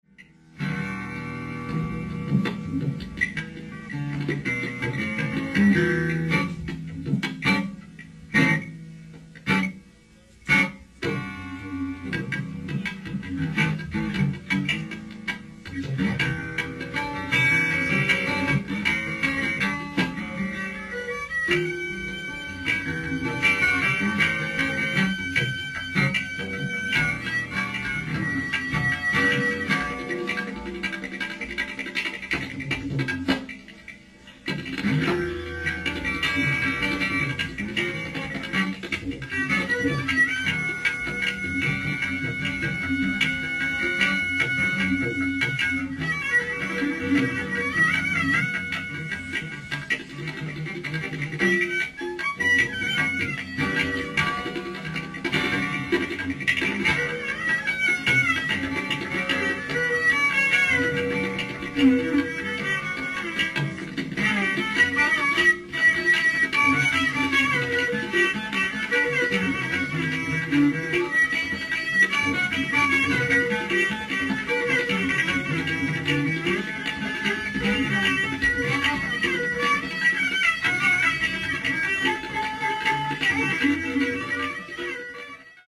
※試聴用に実際より音質を落としています。
violin
tabla
perc